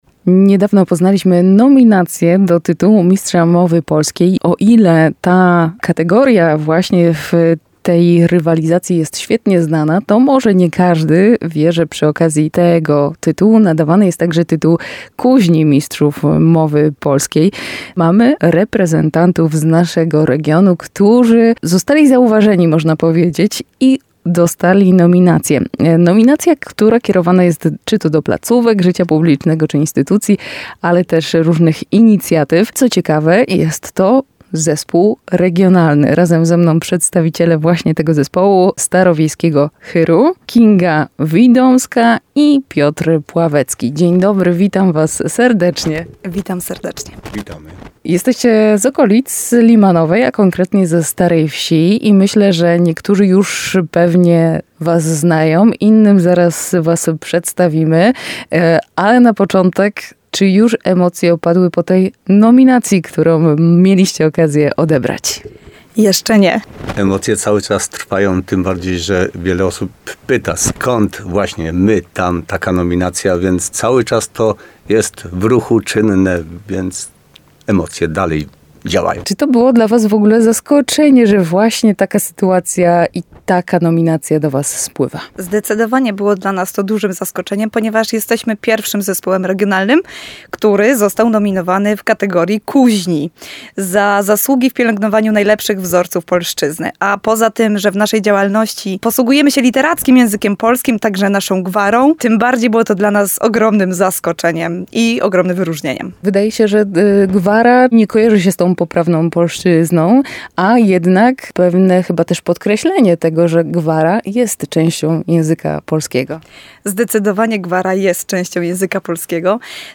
rozmowa_starowiejski_hyr.mp3